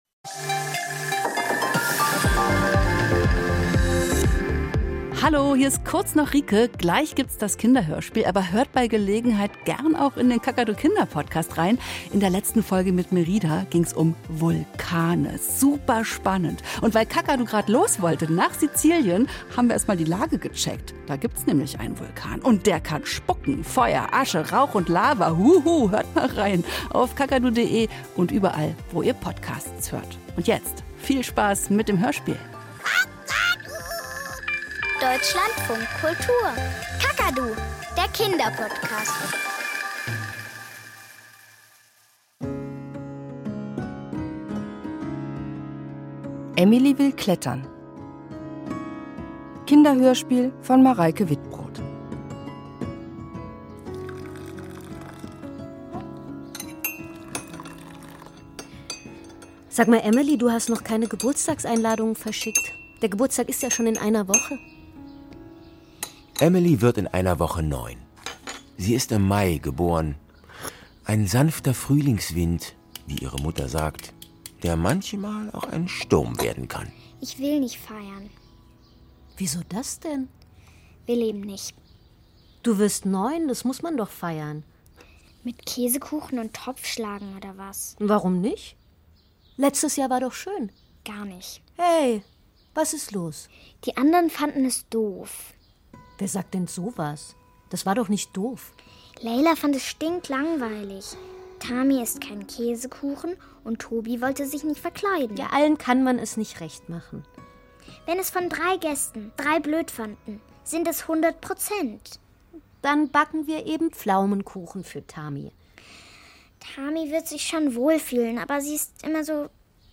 Kinderhörspiel - Emily will klettern